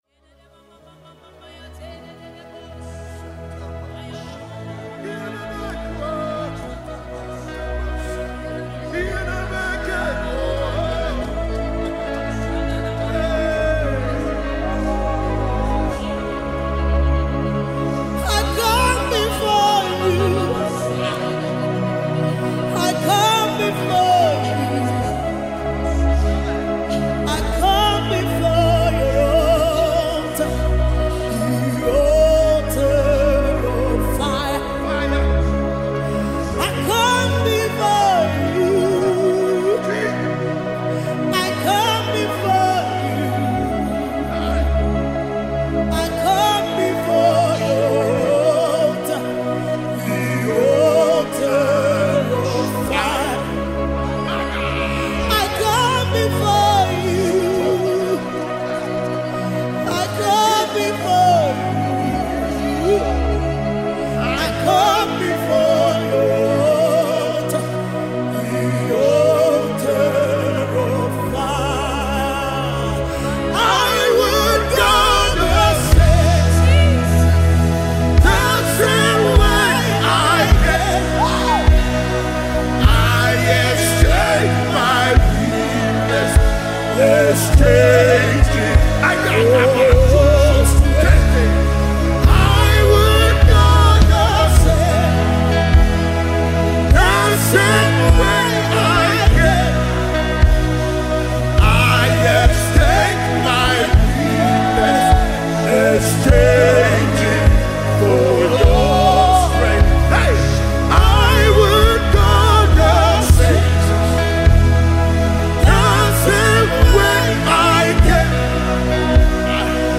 worship group
Re-ignite your passion with this new sound of revival.